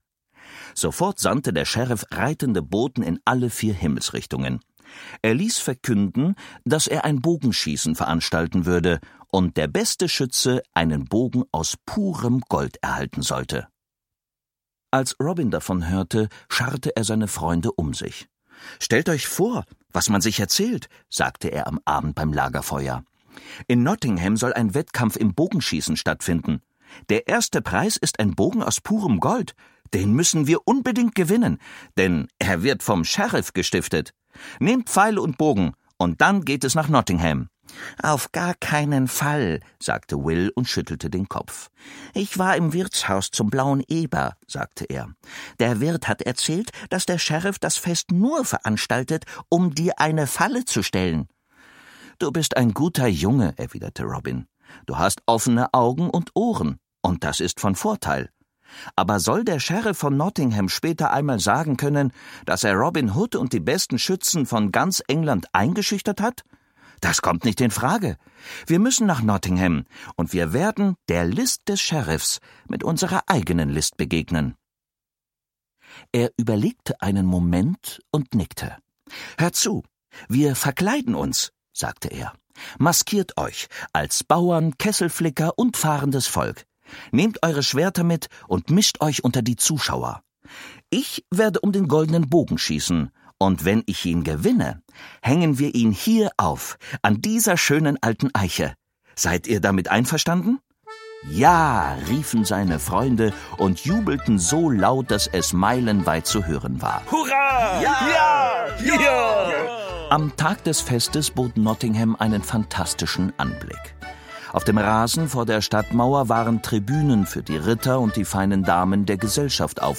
Schlagworte Hörbuch; Lesung für Kinder/Jugendliche • Hörbuch; Märchen/Sagen • Kinder/Jugendliche: Märchen, Sagen, Legenden • Nottingham • Robin Hood • Robin Hood; Kinder-/Jugendlit.